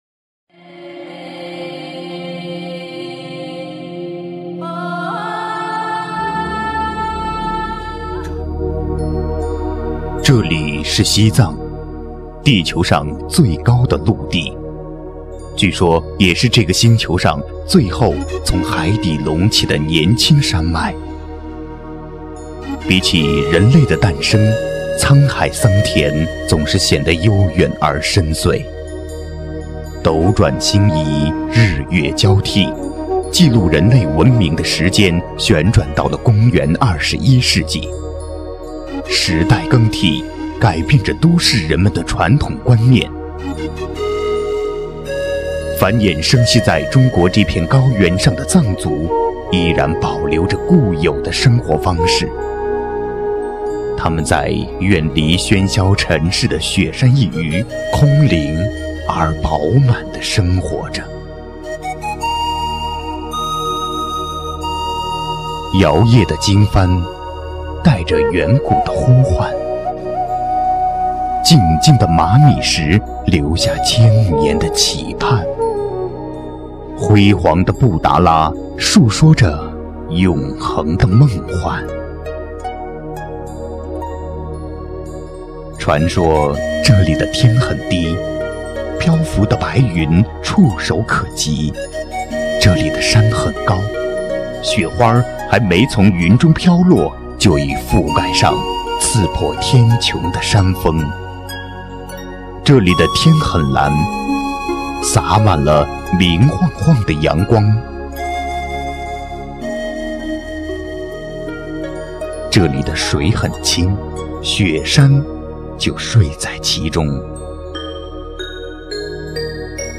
• 男S337 国语 男声 纪录片-梦起西藏-成熟 低沉|大气浑厚磁性|沉稳|娓娓道来